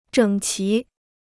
整齐 (zhěng qí) Free Chinese Dictionary